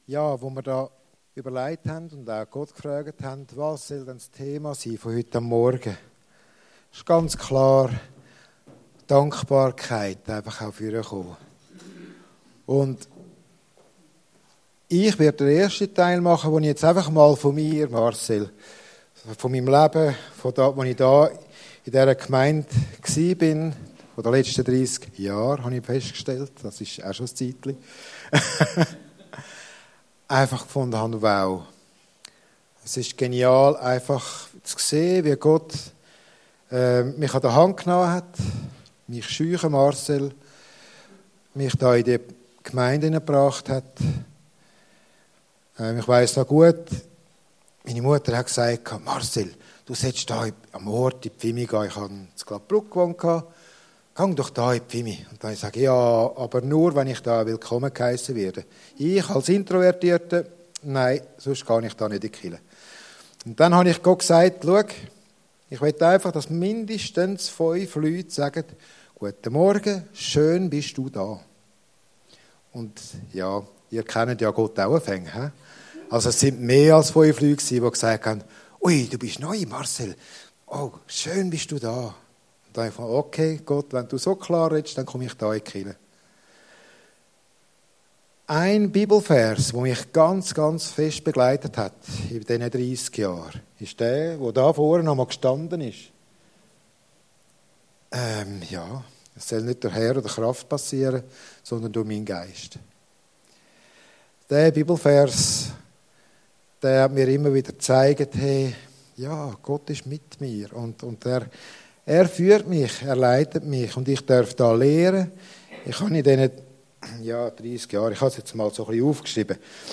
Gottesdienst der Neubrunnen Church Mehr als alles andere behüte dein Herz 7.